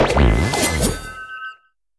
Cri de Malvalame dans Pokémon HOME.